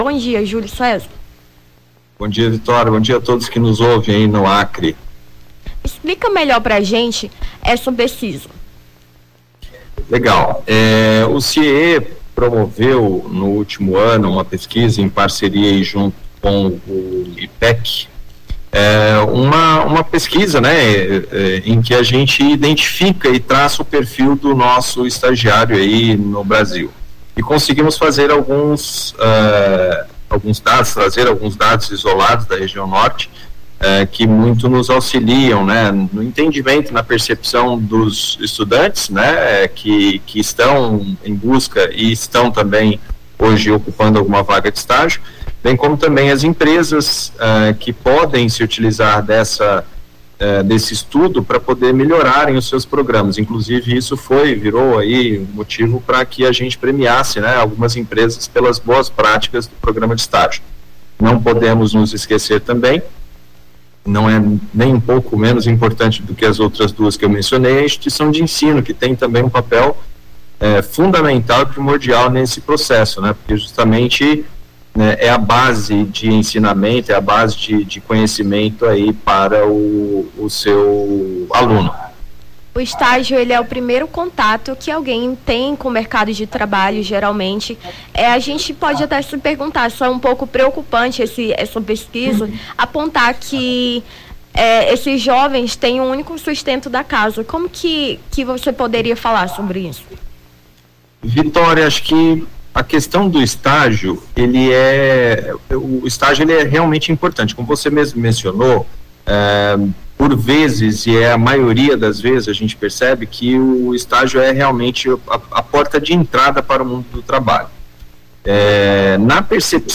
Nome do Artista - CENSURA - ENTREVISTA (ESTAGIARIO NORTE) 16-05-23.mp3